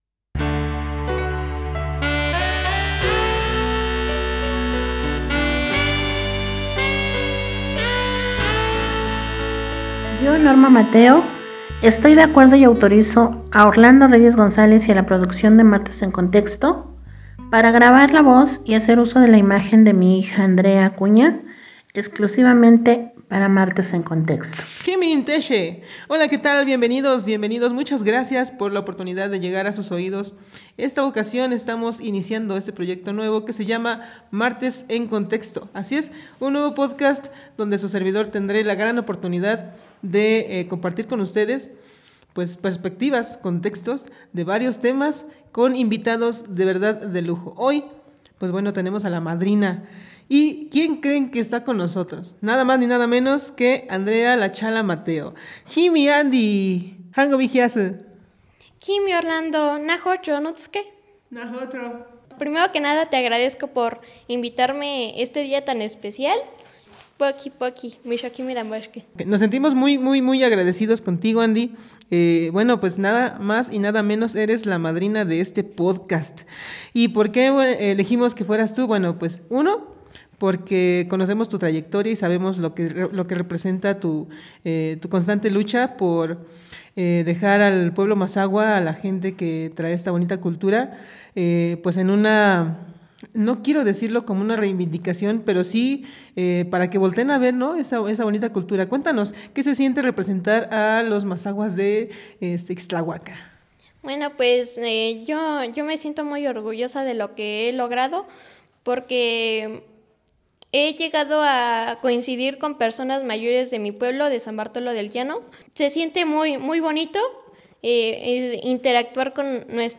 En nuestro primer episodio recibimos en el estudio a la niña que se dejó conquistar por la cultura mazahua; y quien ahora, después de prepararse continuamente, dignamente representa.
Locación: NEAR OFFICE.